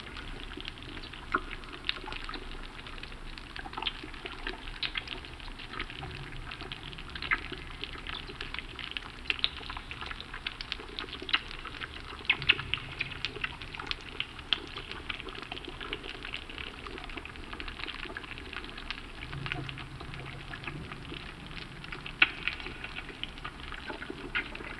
cave_ambience_loop_03.wav